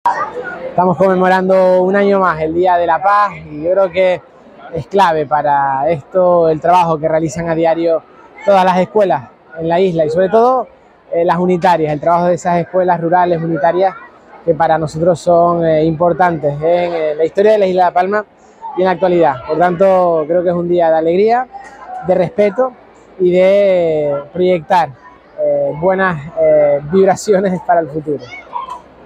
El presidente del Cabildo y de la Fundación, Mariano Zapata, destaca que esta primera acción se desarrolló en la tradicional celebración del Colectivo de Escuelas Unitarias del Día de la Paz, que acogió la Plaza de San Fernando y la Plaza de España de Santa Cruz  de La Palma.
Declaraciones Mariano Zapata audio.mp3